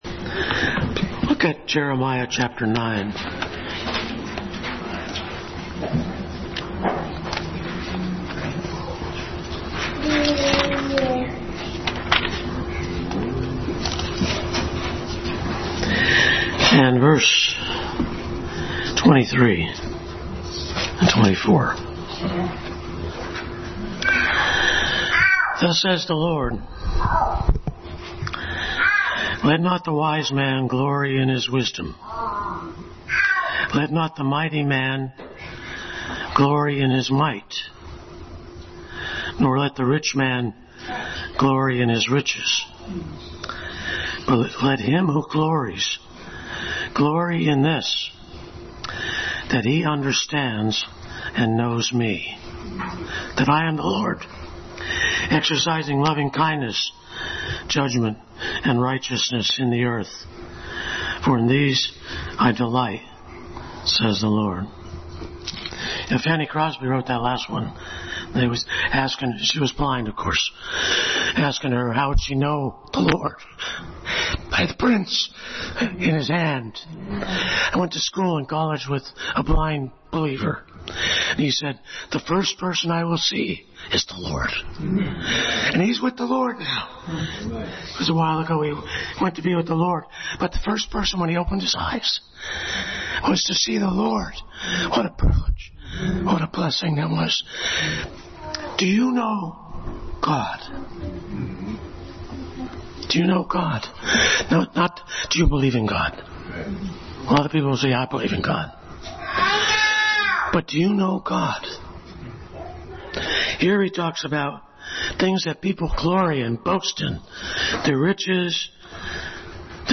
God Knows Me Passage: Jeremiah 9:23-24, Hosea 6:3, John 1:43-51, Psalm 139 Service Type: Family Bible Hour